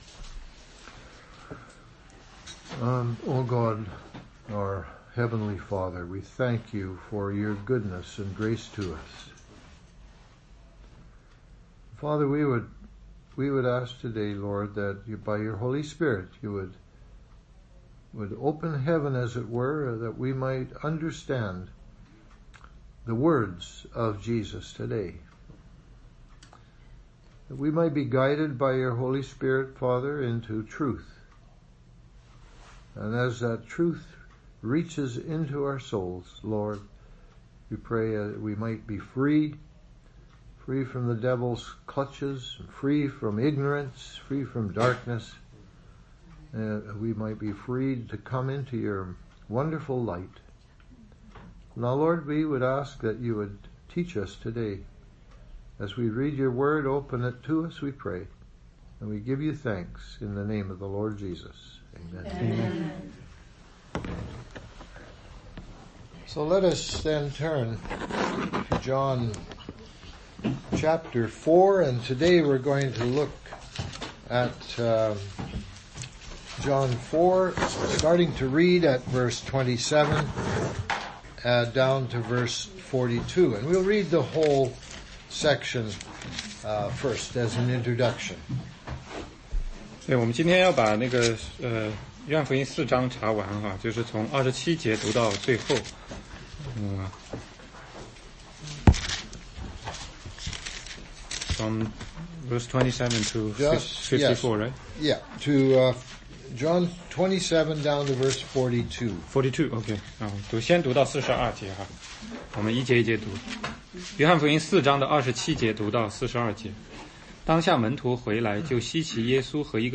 16街讲道录音 - 约翰福音4章27-42